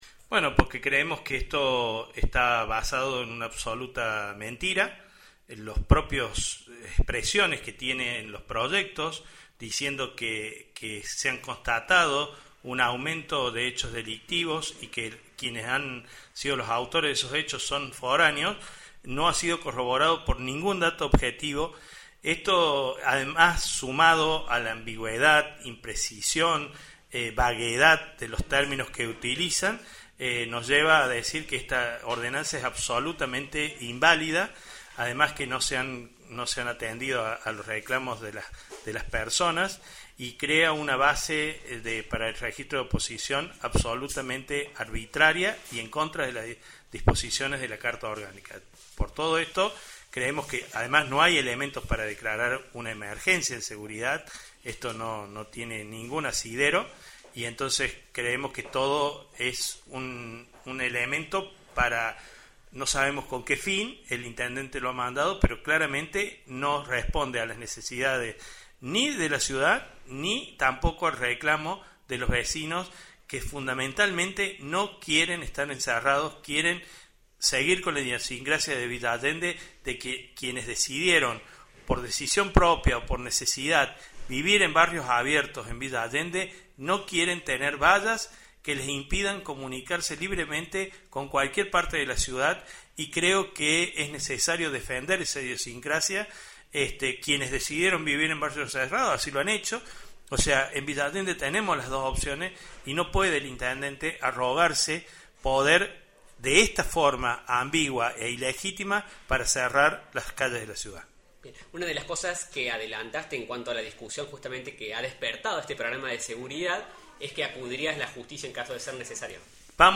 ENTREVISTA A JULIO LOZA, CONCEJAL DE ELEGÍ VILLA ALLENDE